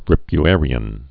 (rĭpy-ârē-ən)